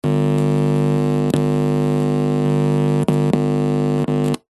Звуки наушников
Тихий динамик компьютера шум треск плохое соединение несколько раз долго